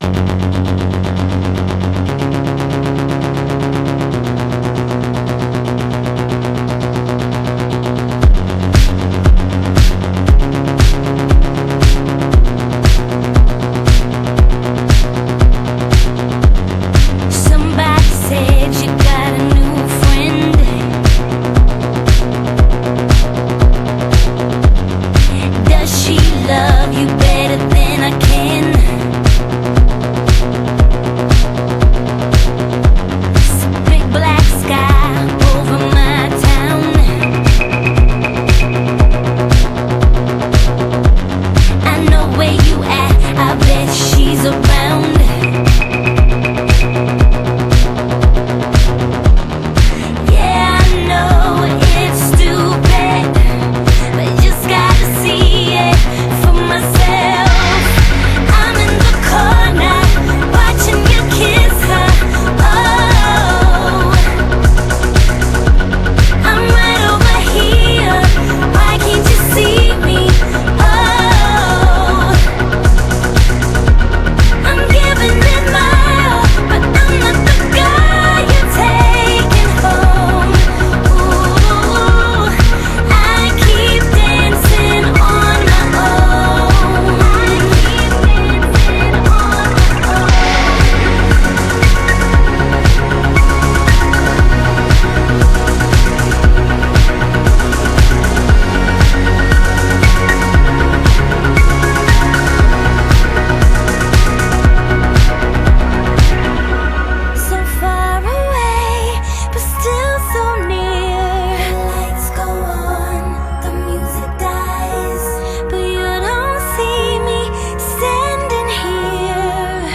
BPM117
Audio QualityMusic Cut